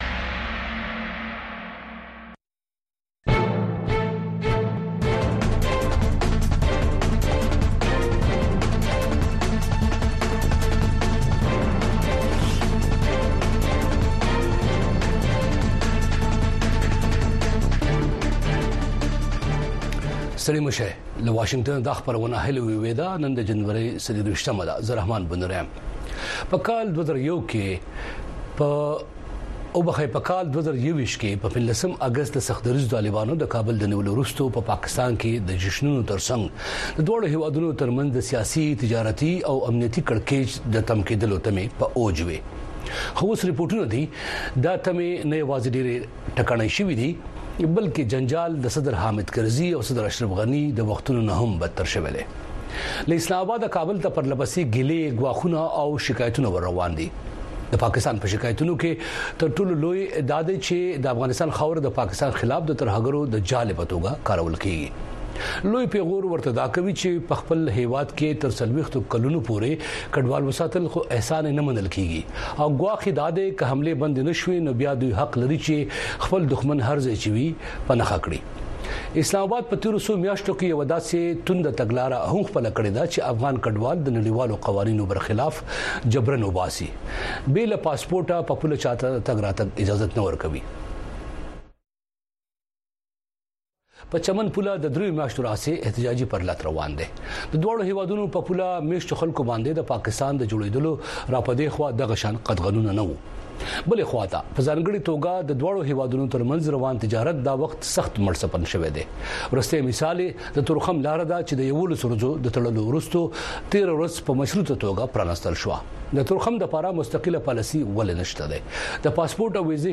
د افغانستان او پاکستان په پوله د پرله پسې جنجالونو علتونه او تجارت ته زیانونو په موضوع بحث